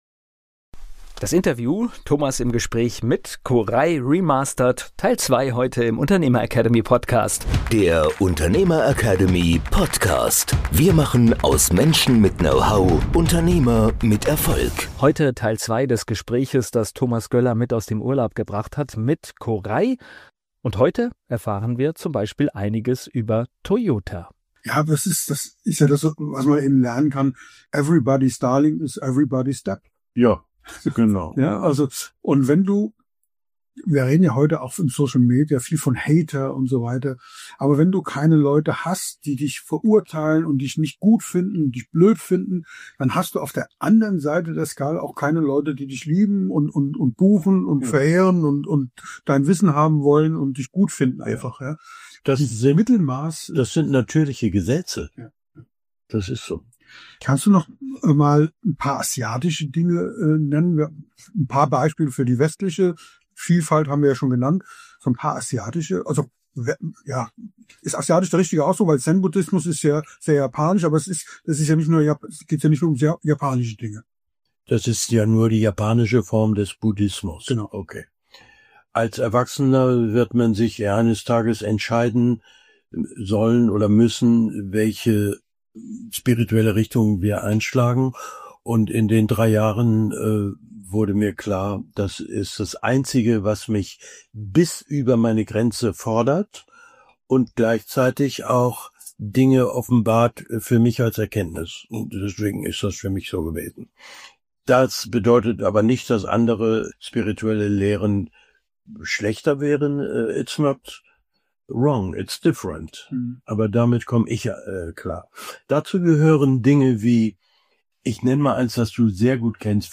Ein Gespräch über Lebenswege, Erkenntnis, Mut und Führung, das bewusst über klassische Managementlogik hinausgeht – und genau deshalb hängen bleibt.